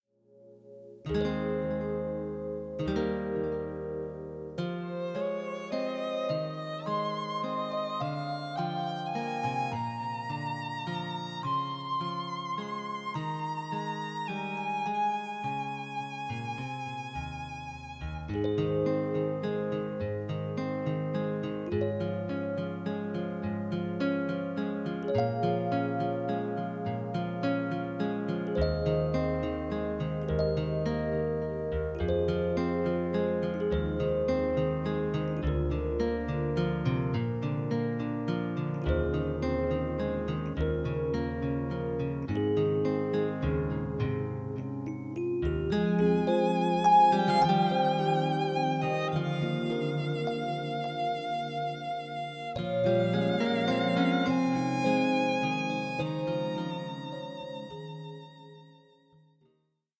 Das Playback-Album zur gleichnamigen Produktion.
Playback ohne Backings, gleiche Reihe 2,99 €